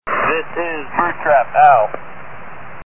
bird_trap.mp3